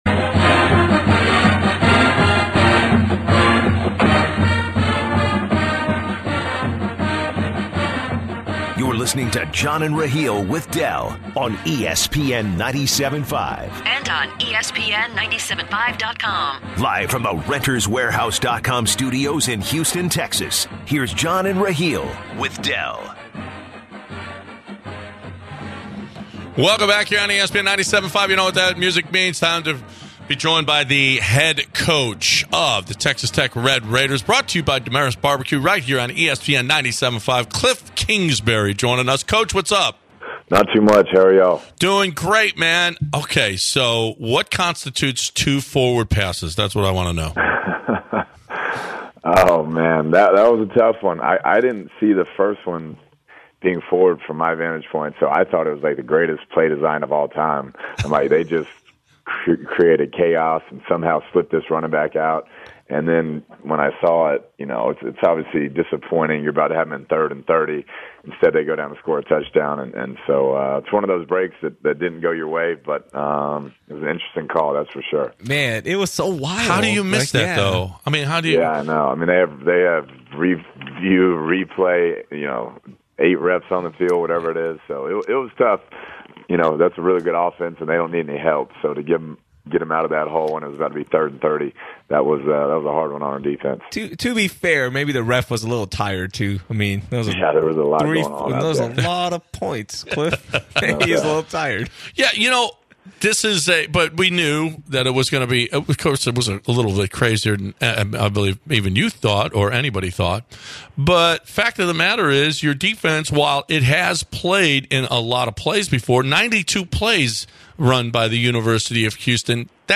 Texas Tech Head Coach Kliff Kingsbury joins the show.